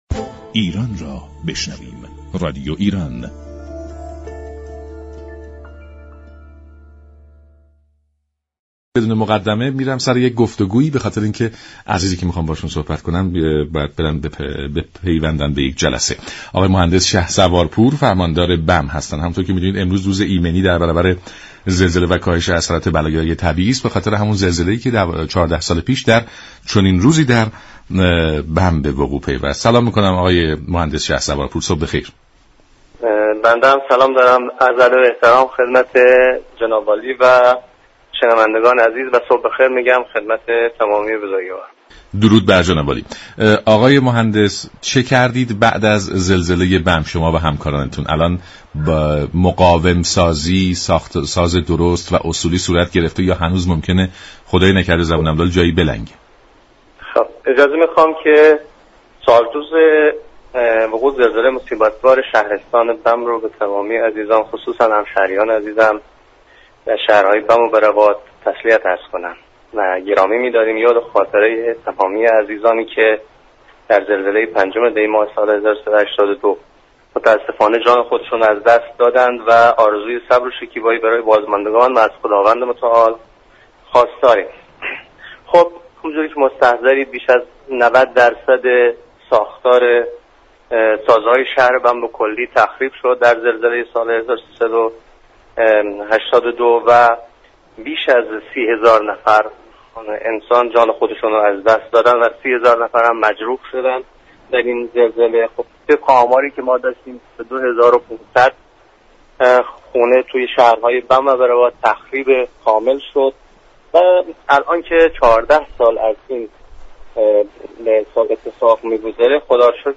شهسوار پور فرماندار شهرستان بم در گفت و گو با برنامه سلام ایران گفت: پس زلزله غم انگیز و بسیار تلخ بم، این استان شاهد اتفاقات خوبی بوده است؛ انتقال آب از سد نسا به بم، اتوبان كرمان_ بم و عملیات گاز رسانی و ... از جمله این موارد بوده است.